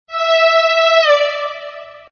danceTwo_strings00.mp3